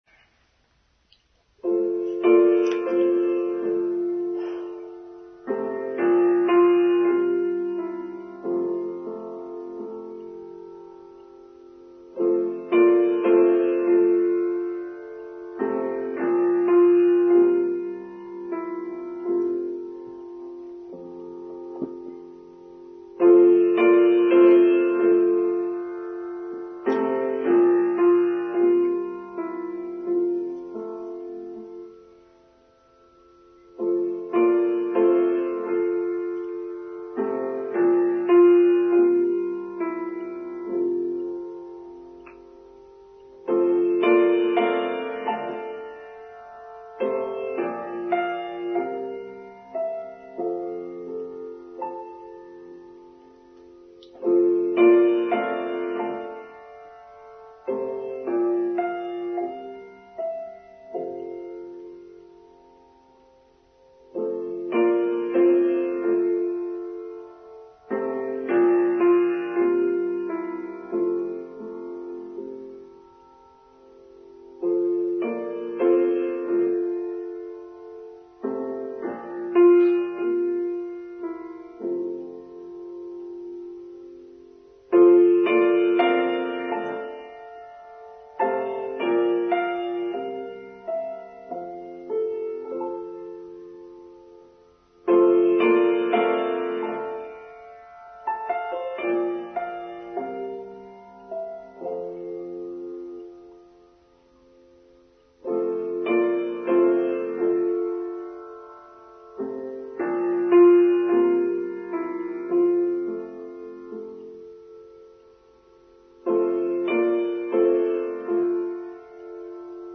Expectations: Online Service for Palm Sunday 2nd April 2023